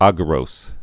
gə-rōs, -rōz, ăgə-)